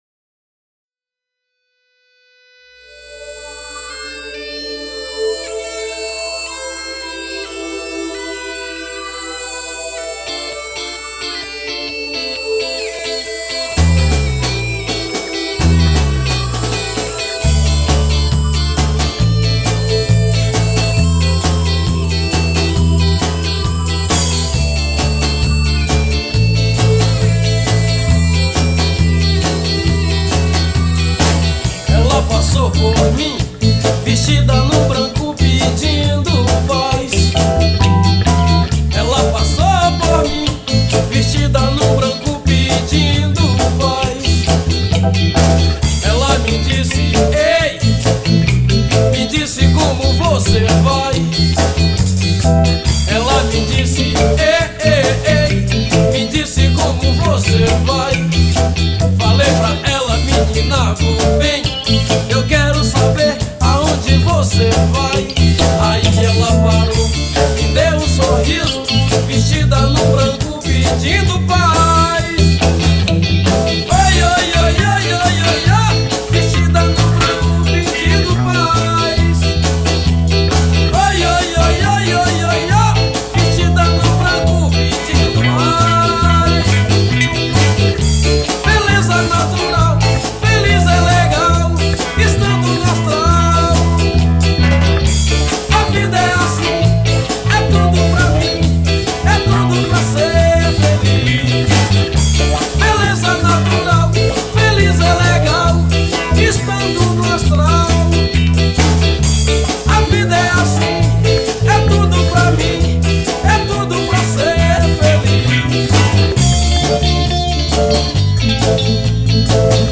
EstiloReggae